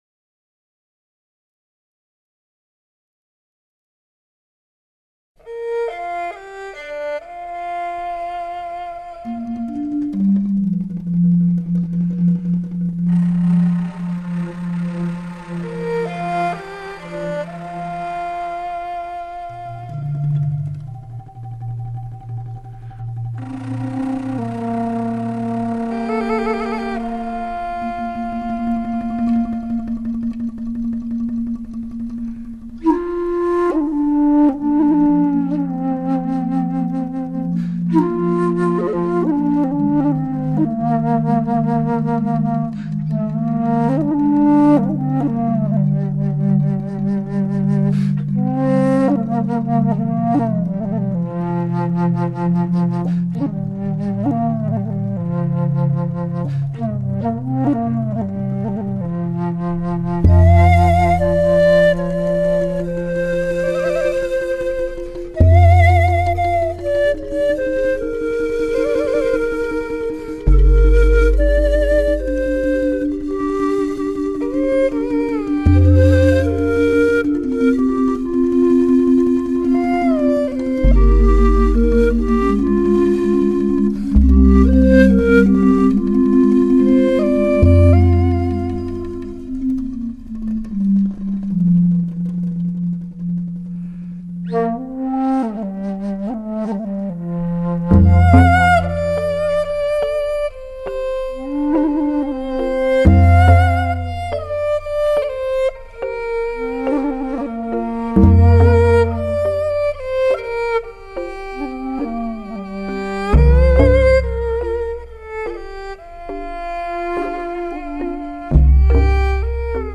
一曲凄美之音--器乐曲
器乐曲